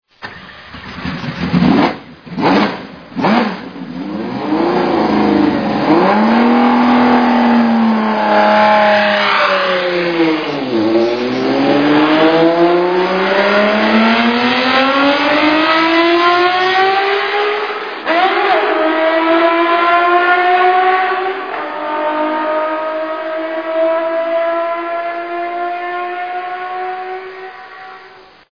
Matra MS80 V12 engine sound
Here you can listen to the beautiful engine noise of the French F1 car. The Matra MS80 with a V12 engine noise (1969).
MatraV12.mp3